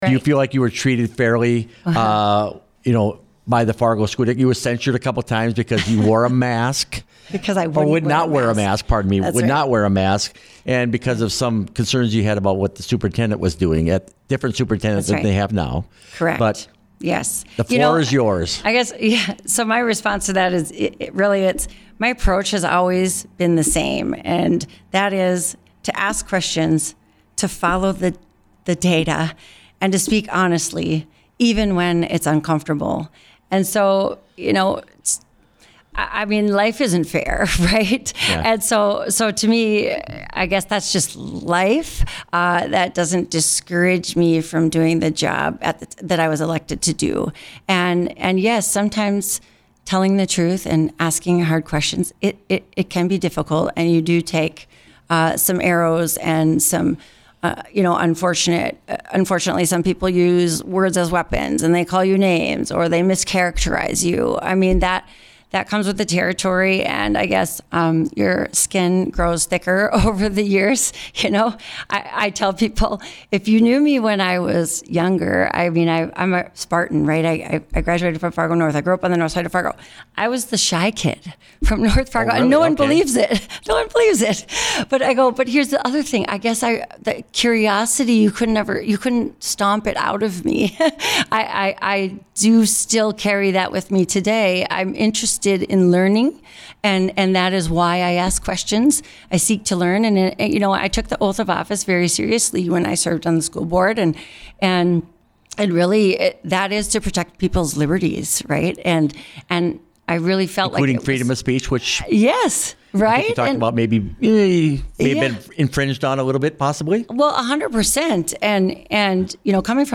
benson-with-flakoll.mp3